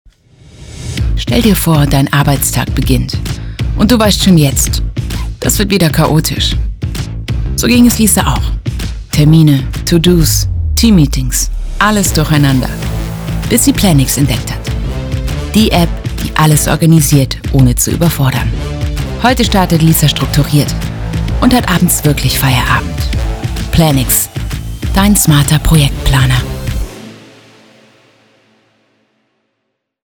Interesse an Hörbuch-Demo auf Social-Media?
Deine Stimme finde ich toll.